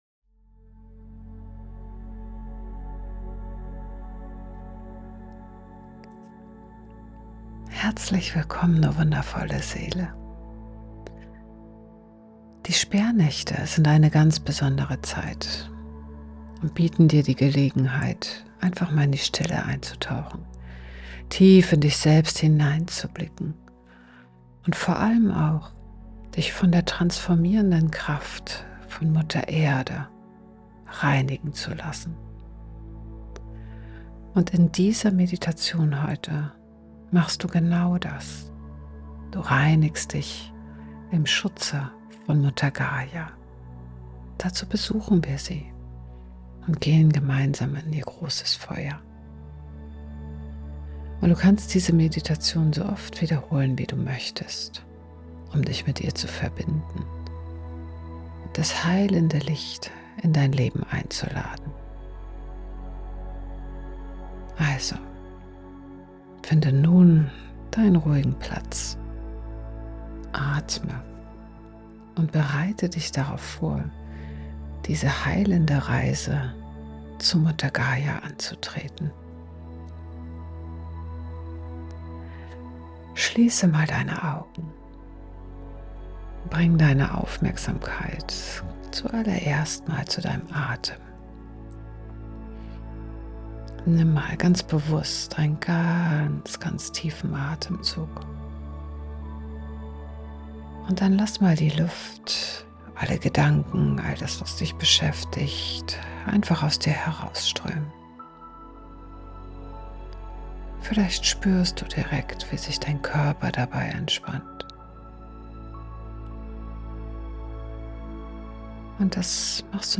Deine Meditation